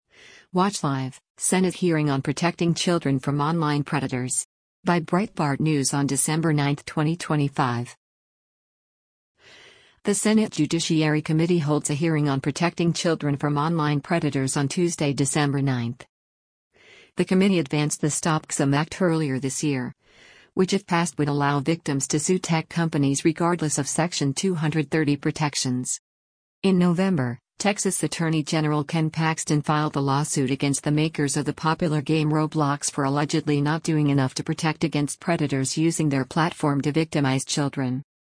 The Senate Judiciary Committee holds a hearing on protecting children from online predators on Tuesday, December 9.